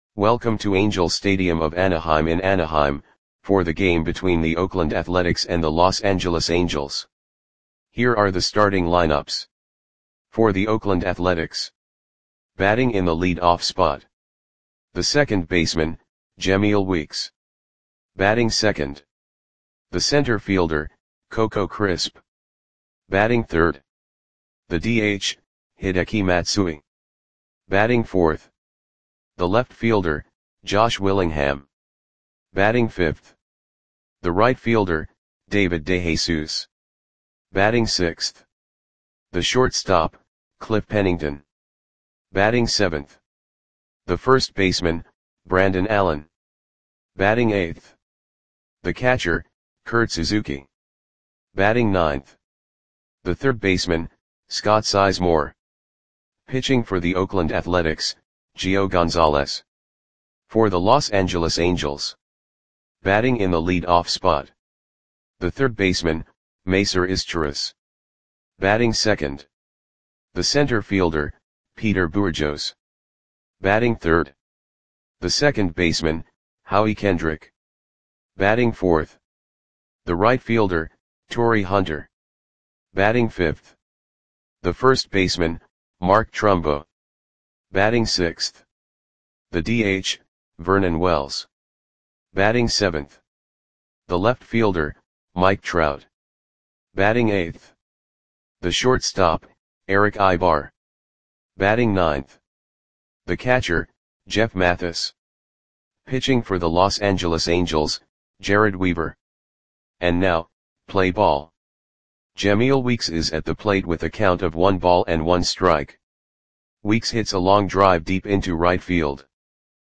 Audio Play-by-Play for Los Angeles Angels on September 23, 2011
Click the button below to listen to the audio play-by-play.